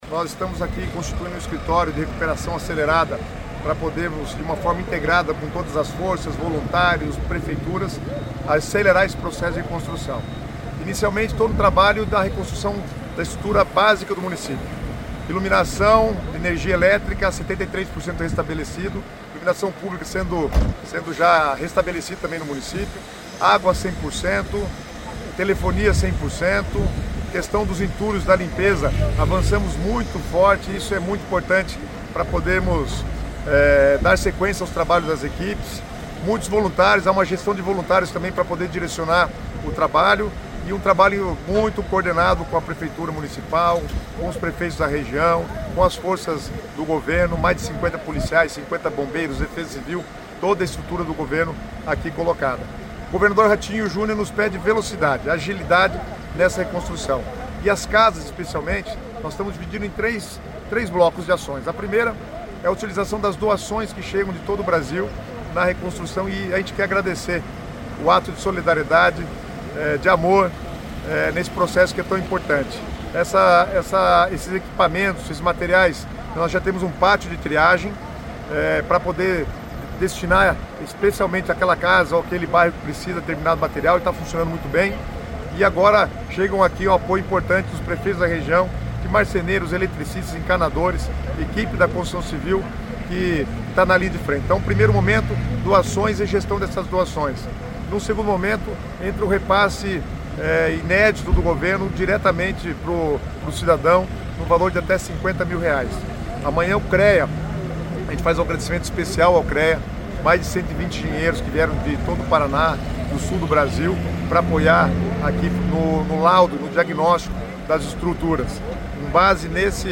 A Secretaria das Cidades do Paraná instalou em Rio Bonito do Iguaçu, cidade do centro-sul do estado destruída por um tornado, o Escritório de Reconstrução Acelerada. O objetivo do escritório é coordenar as ações de reconstrução de casas, comércios e prédios públicos. Ouça o que diz o secretário das Cidades, Guto Silva: